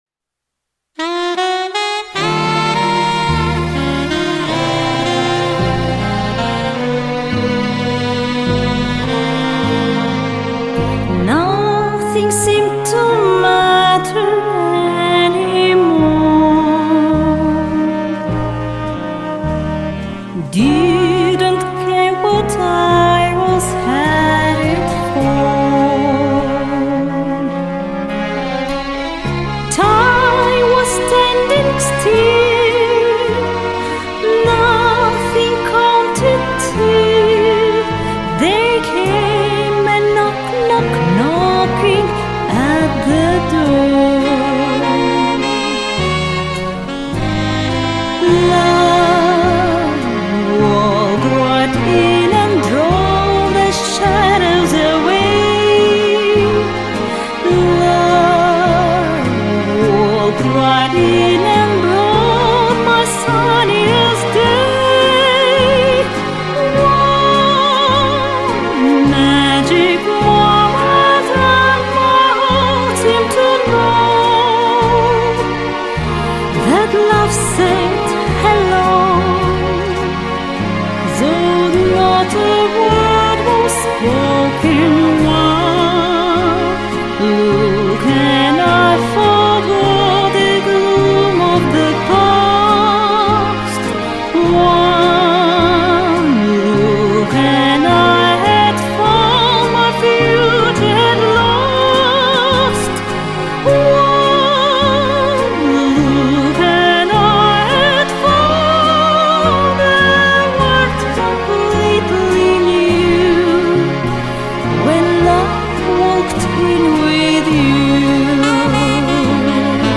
Женский
Сопрано